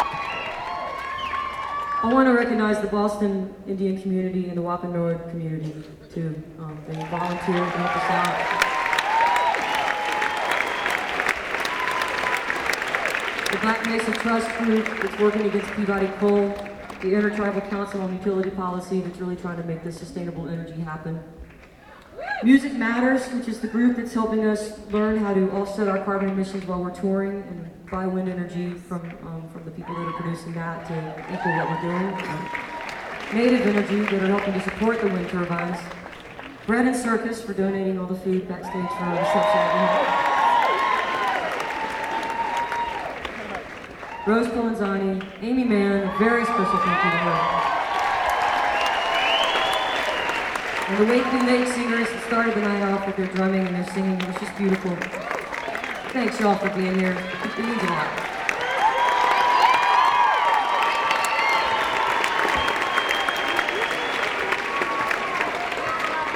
lifeblood: bootlegs: 2002-12-10: orpheum theatre - boston, massachusetts